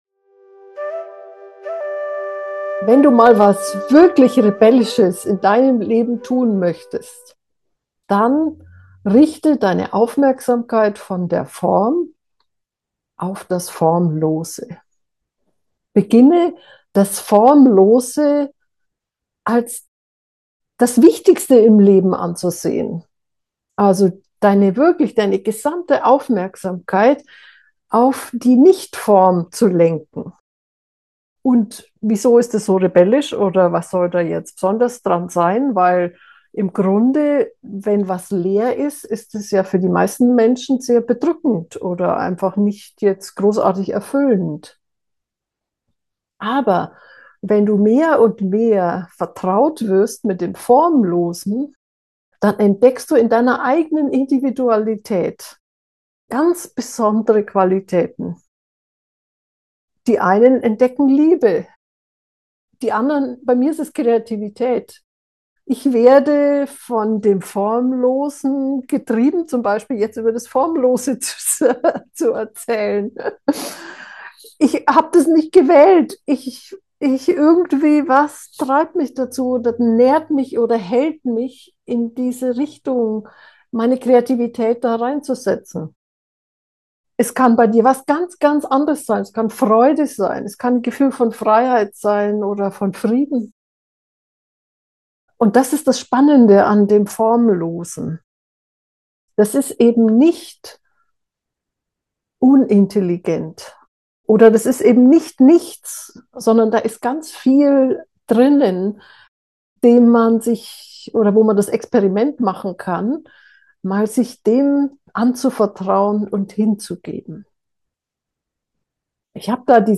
das-formlose-gefuehrte-meditation.mp3